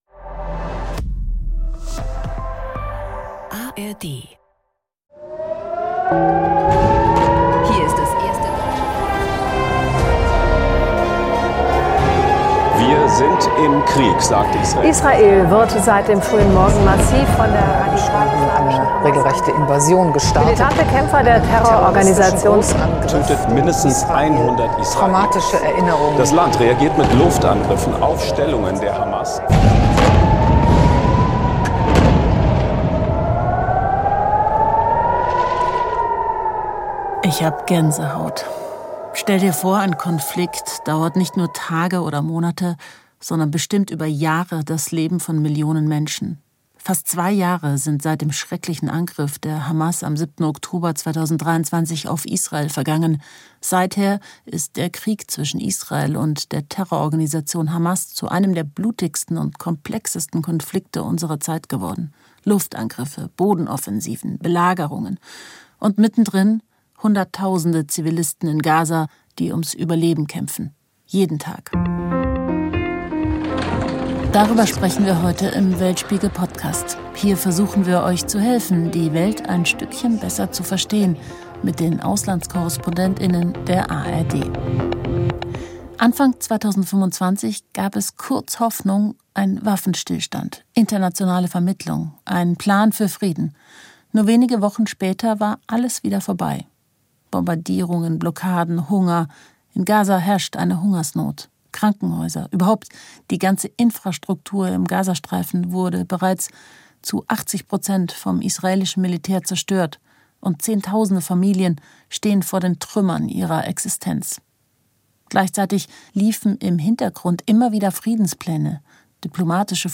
Moderation: Natalie Amiri